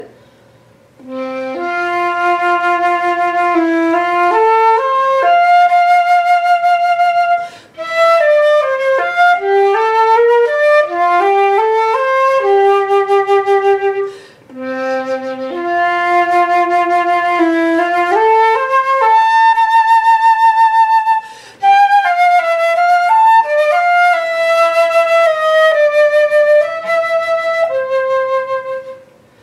fuvola_vagva.mp3